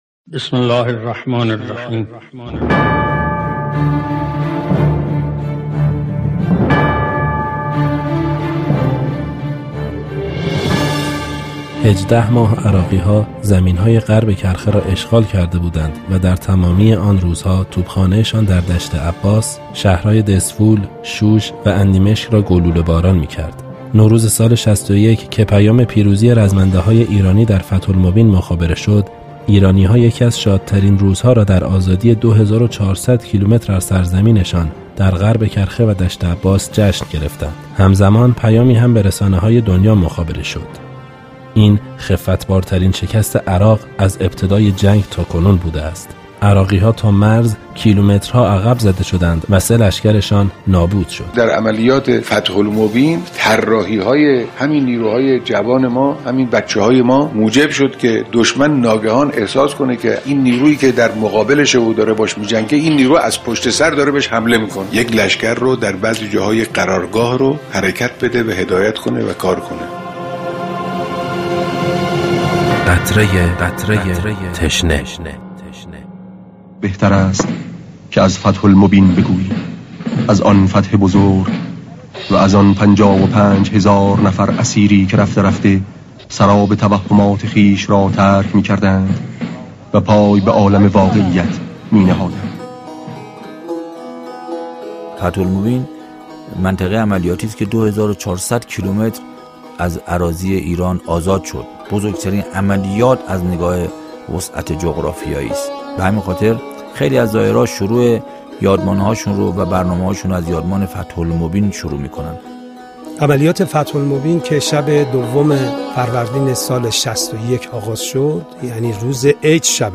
صوت روایتگری عملیات فتح المبین